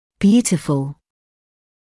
[‘bjuːtɪfl][‘бйуːтифл]красивый, прекрасный